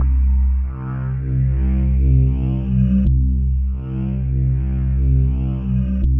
Index of /90_sSampleCDs/USB Soundscan vol.13 - Ethereal Atmosphere [AKAI] 1CD/Partition E/11-QUARTZ